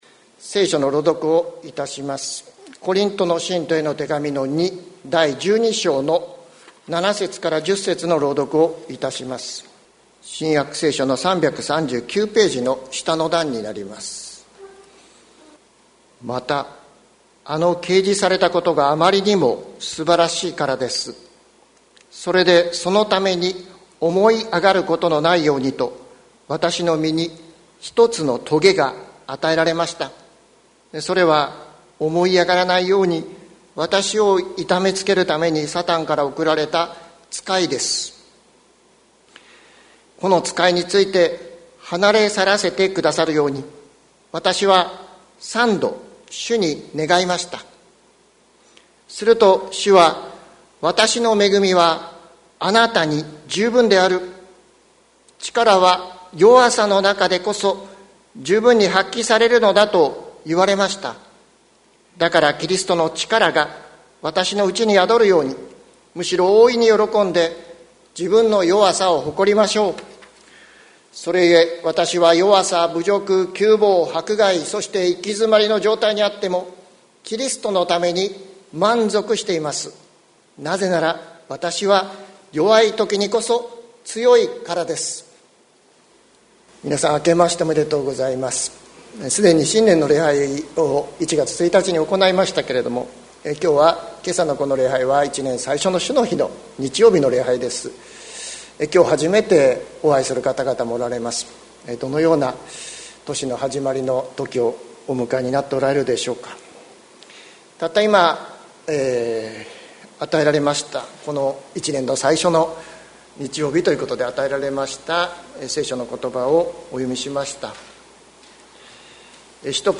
2021年01月03日朝の礼拝「恵みはあなたに十分」関キリスト教会
説教アーカイブ。